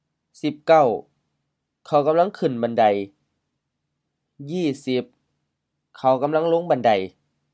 กำลัง gam-laŋ M-HR กำลัง auxiliary indicating continuous or progressive action
บันได ban-dai M-M บันได stairs, staircase, ladder